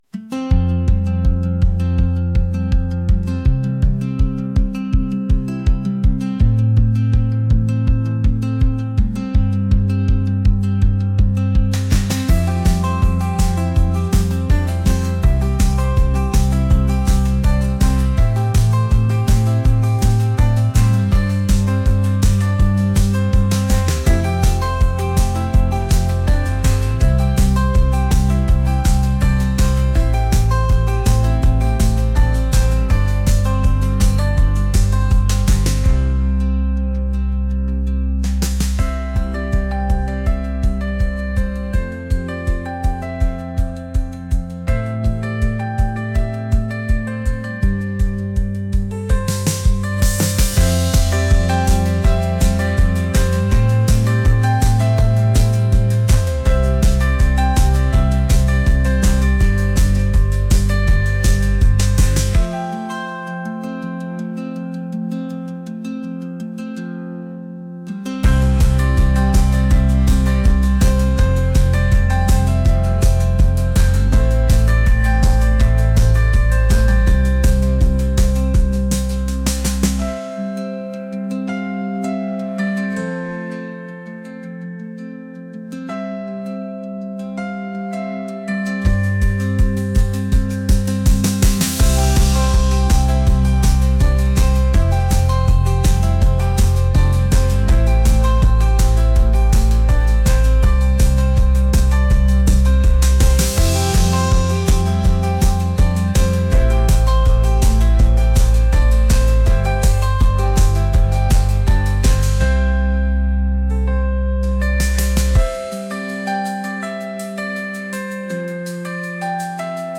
indie | folk